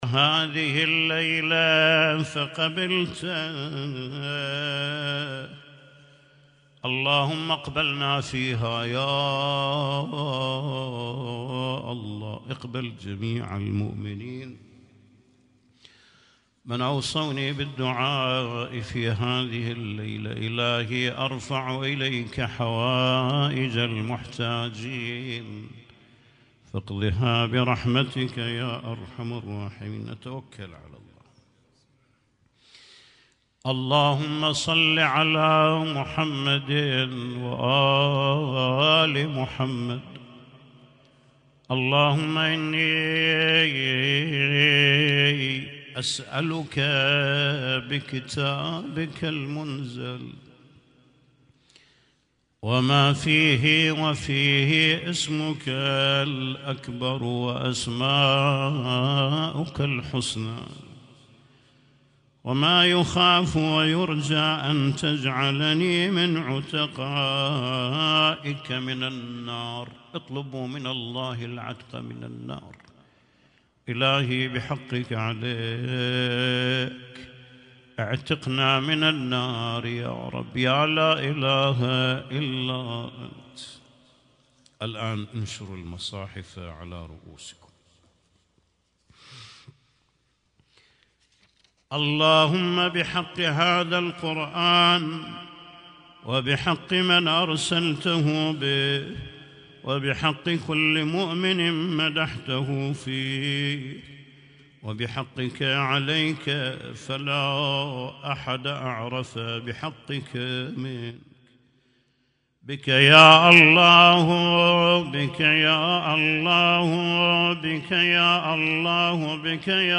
Husainyt Alnoor Rumaithiya Kuwait
اسم التصنيف: المـكتبة الصــوتيه >> الادعية >> ادعية ليالي القدر